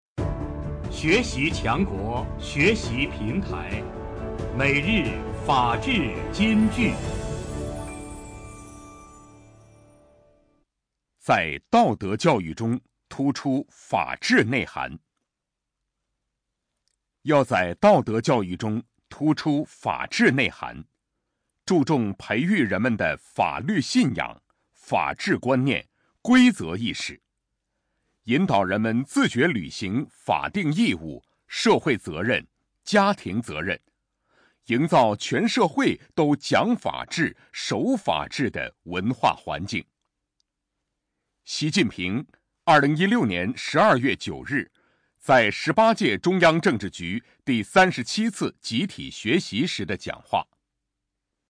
每日法治金句（朗读版）|在道德教育中突出法治内涵 _ 学习宣传 _ 福建省民政厅